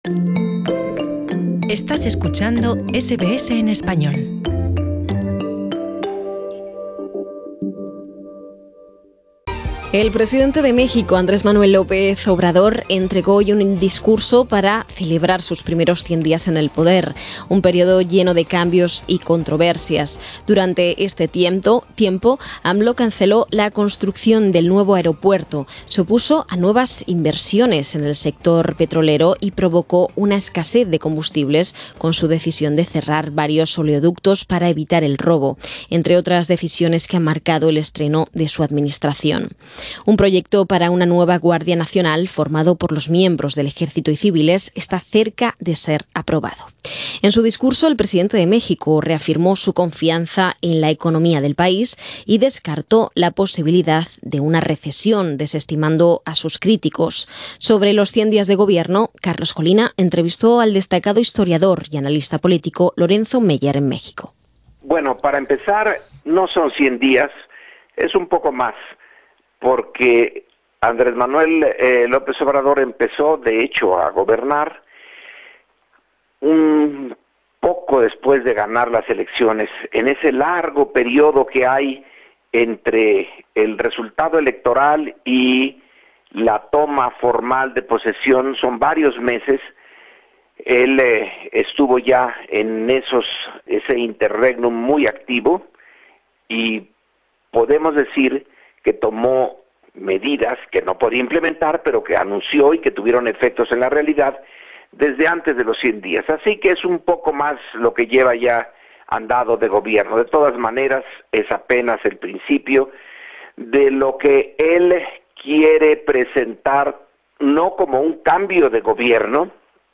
Entrevista con el destacado historiador y analista político, Lorenzo Meyer, en México.